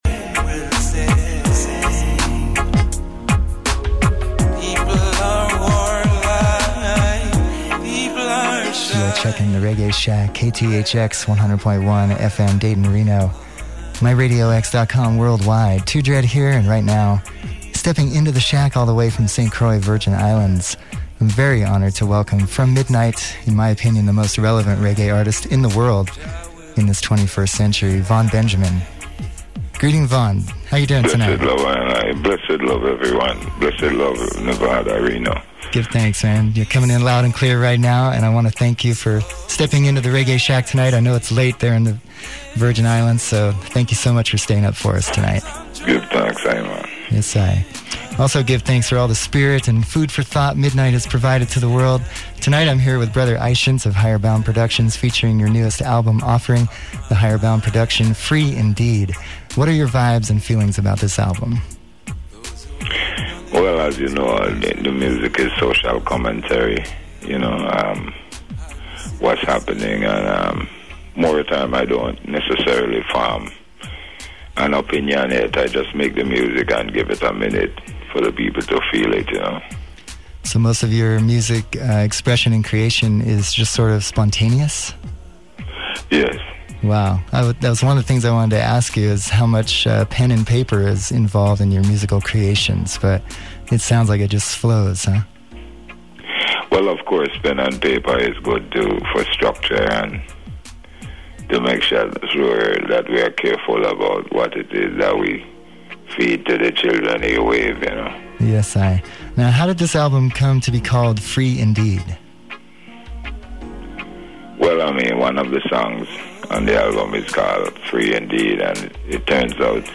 Reggae Shack Interview